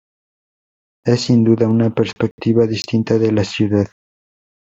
pers‧pec‧ti‧va
/peɾspeɡˈtiba/